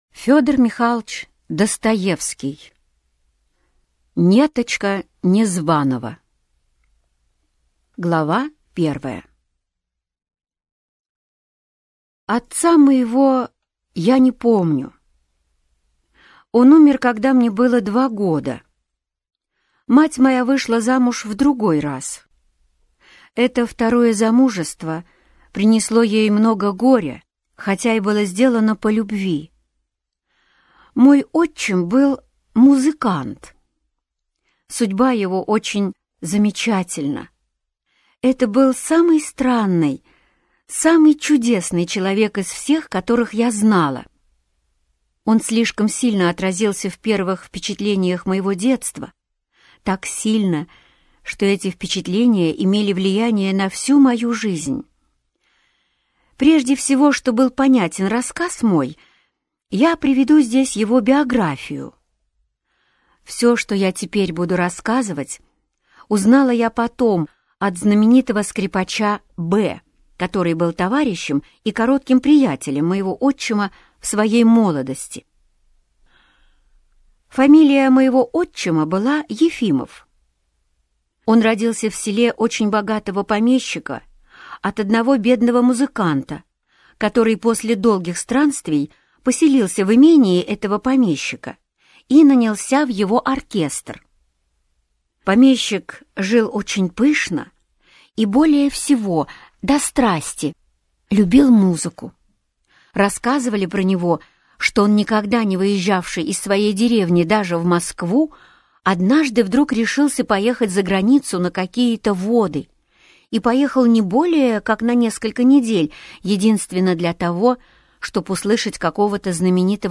Аудиокнига Неточка Незванова - купить, скачать и слушать онлайн | КнигоПоиск